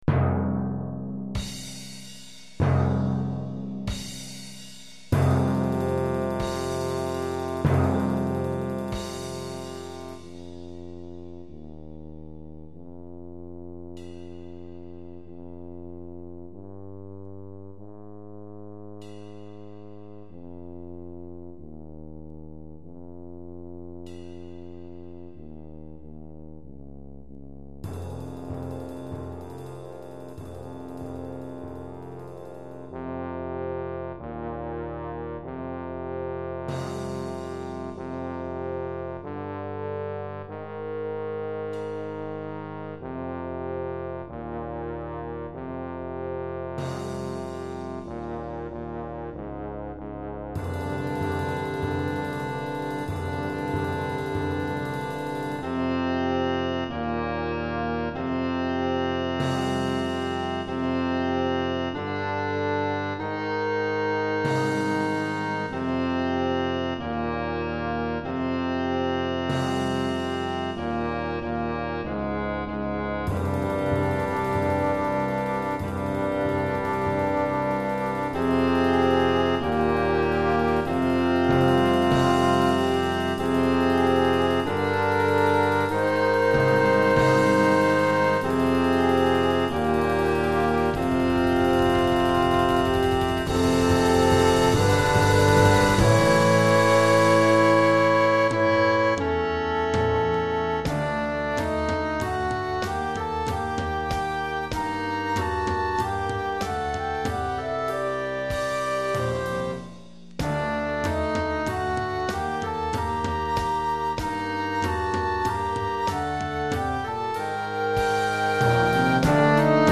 Orchestre d'Harmonie 4 Voix et 4 Percussion